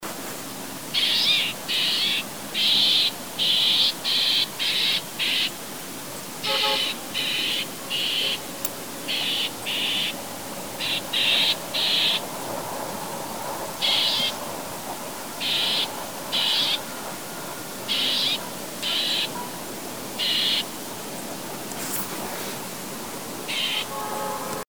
Cris d’alarme enregistrés le 02 février 2012, en Chine, province du Guangxi, à Shanli près de la ville de Yizhou.